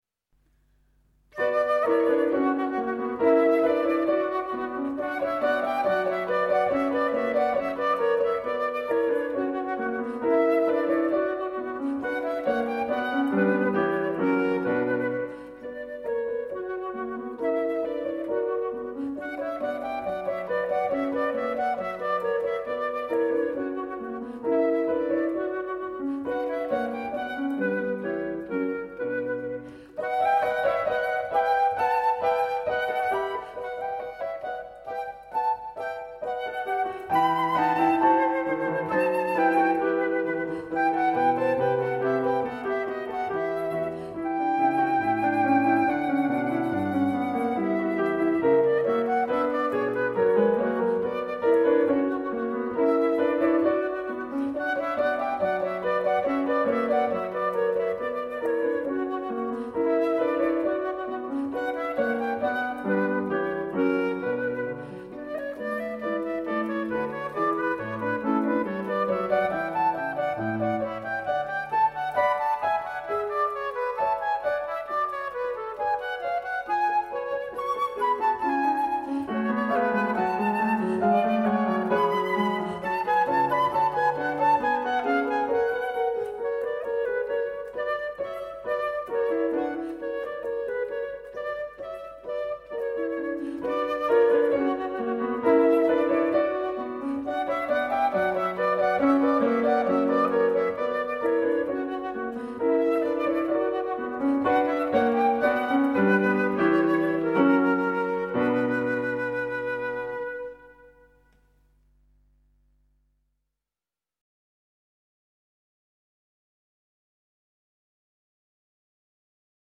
小品式的音乐，长笛、钢琴和竖琴的组合
录音的音色也是赏心悦耳
它的音色柔美，金属光泽中透出一种人性化的特质
这三件乐器的录音效果则非比寻常，声部的平衡感和音质的透彻感绝可媲美世界同类制作。